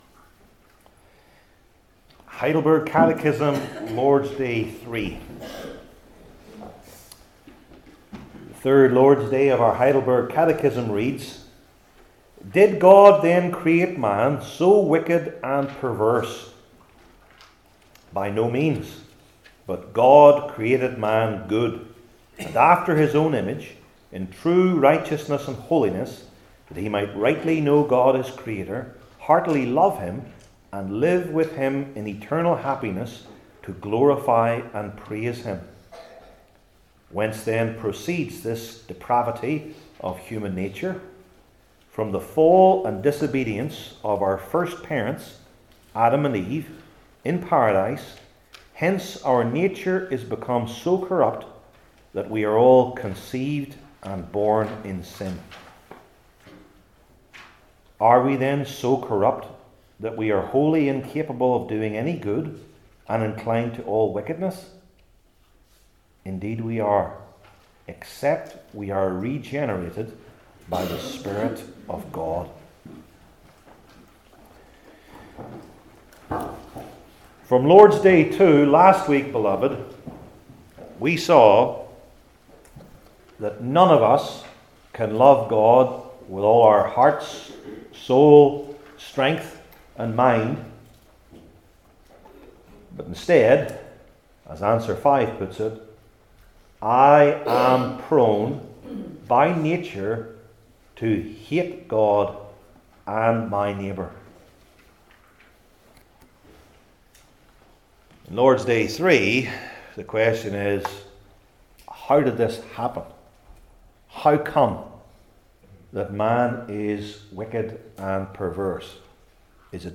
Heidelberg Catechism Sermons I. The Meaning of God’s Image II.